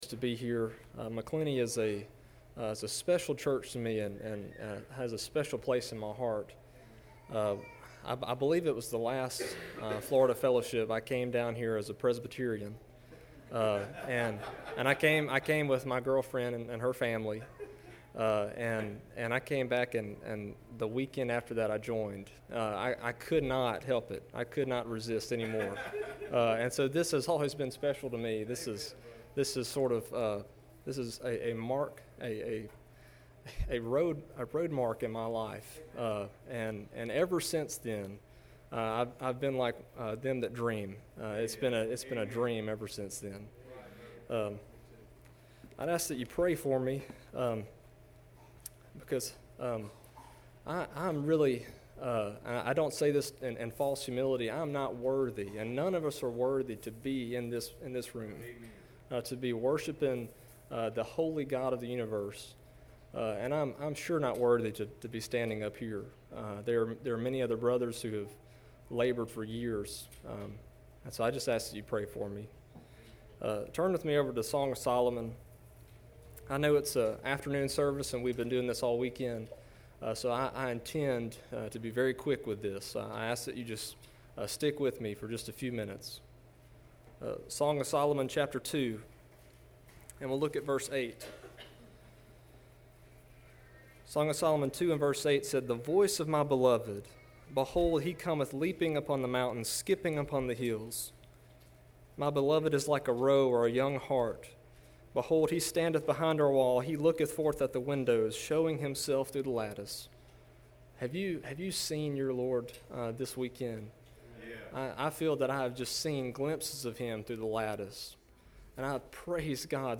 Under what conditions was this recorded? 02/10/18 Saturday Afternoon Florida Fellowship Meeting